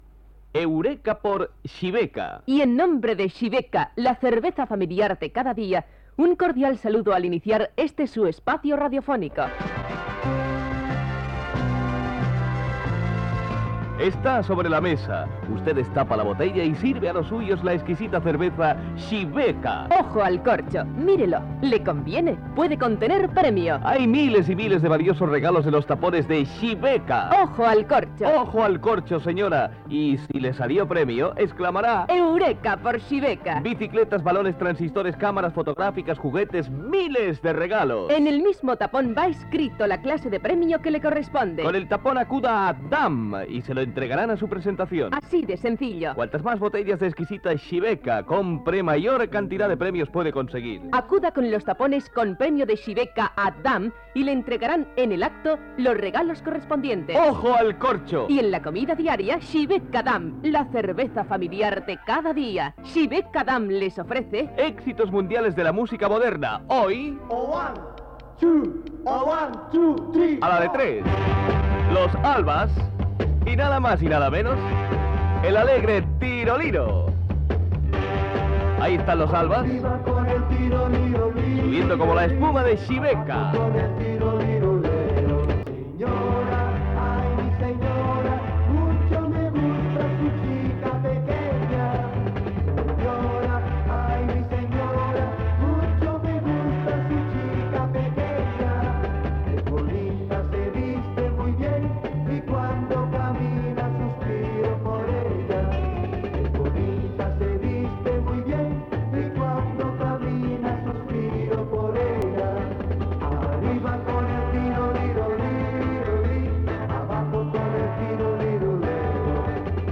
Espai musical publicitari de Xibeca Damm